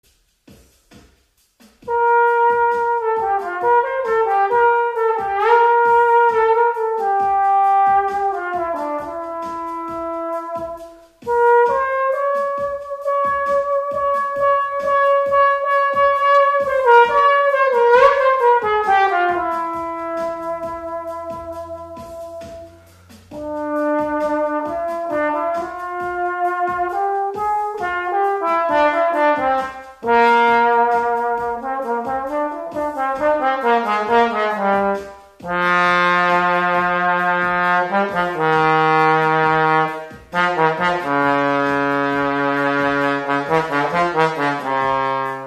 La familia del Viento Metal se caracteriza por el sonido que viene generado por las vibraciones de los labios, producidas en el interior de una boquilla, por el efecto del aria introducida en el.
TROMBÓN
Trombone.mp3